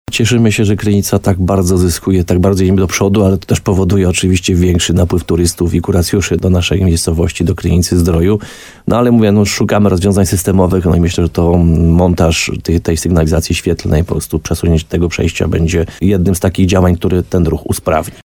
– Przejście dla pieszych przy rondzie zdrojowym – od strony ul. Piłsudskiego, które znacznie spowalniało ruch, zostanie zamknięte, a turyści będą mogli przechodzić przez nowe przejście na tej samej ulicy obok apteki – mówi Piotr Ryba, burmistrz Krynicy-Zdroju.